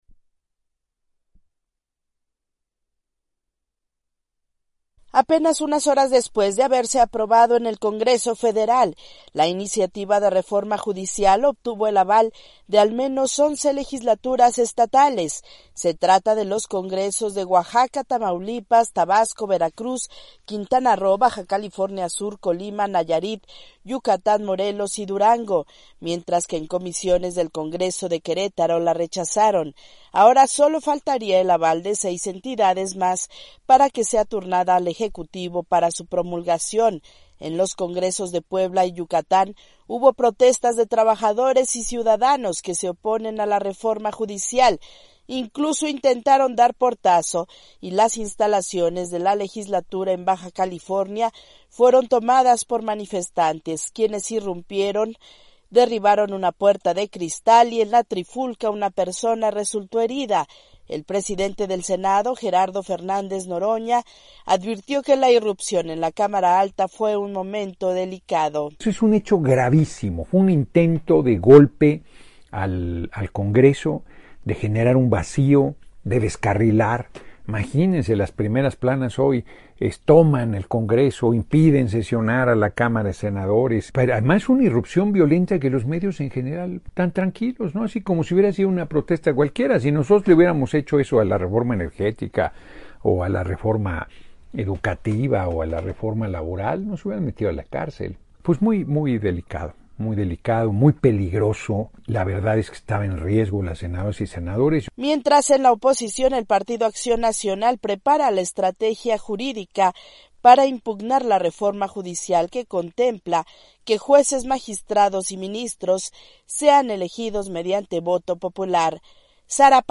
AudioNoticias
La reforma al poder judicial en México avanza a la recta final para ser aprobada, pese a protestas e incluso toma de instalaciones. Desde Ciudad de México informa la corresponsal de la Voz de América